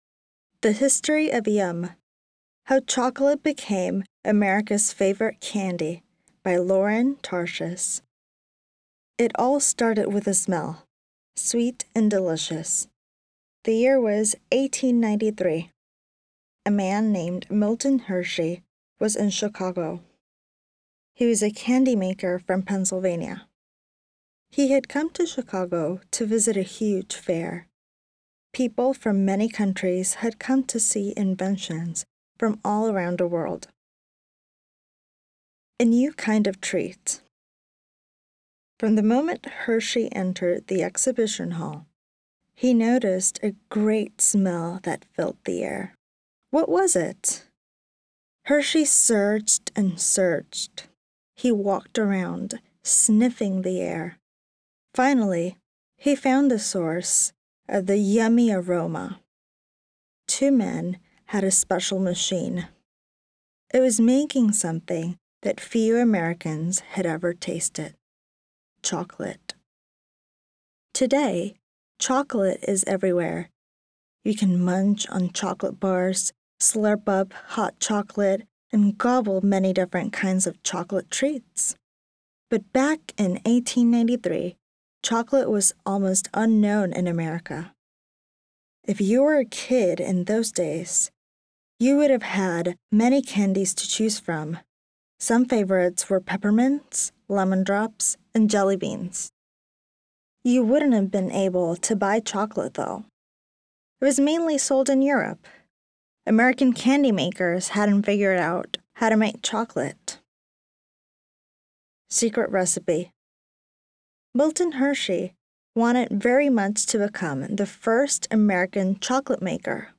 Story-Read-Aloud-PairedTexts-590.mp3